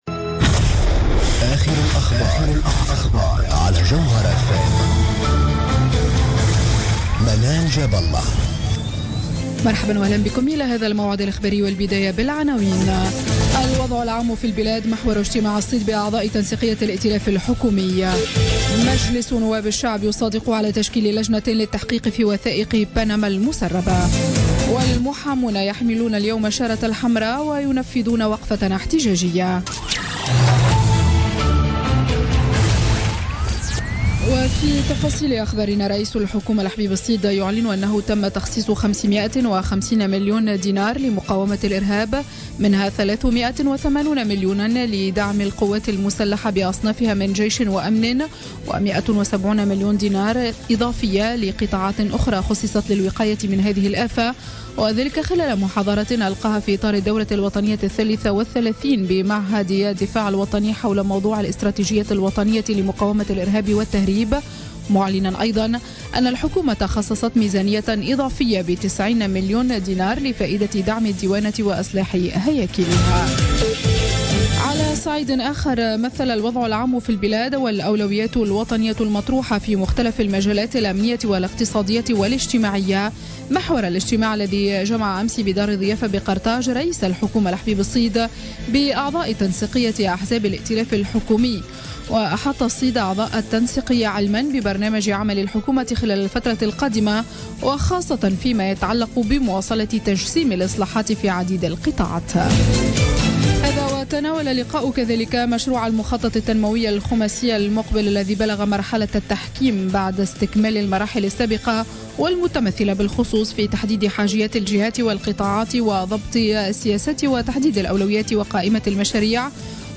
Journal Info 00h00 du vendredi 8 avril 2016